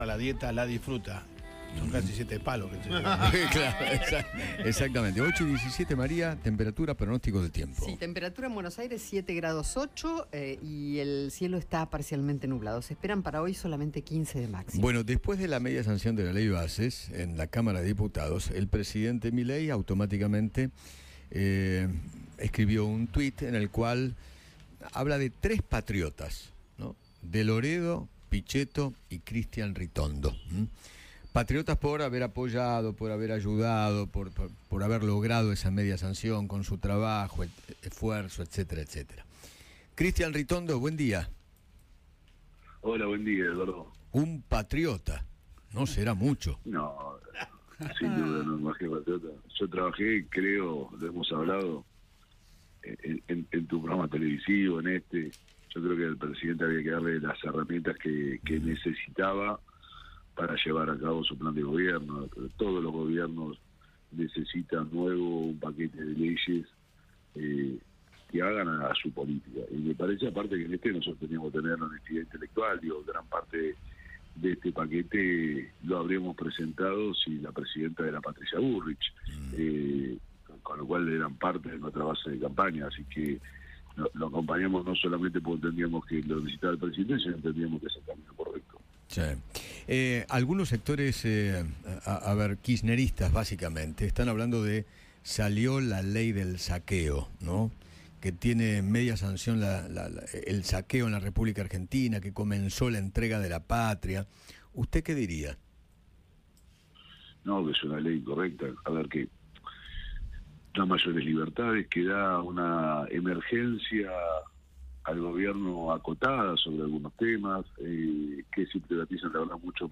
Cristian Ritondo, presidente del Bloque PRO, habló con Eduardo Feinmann sobre los dichos de Javier Milei, quien lo calificó como “patriota” por haber apoyado y logrado la media sanción de la Ley Bases.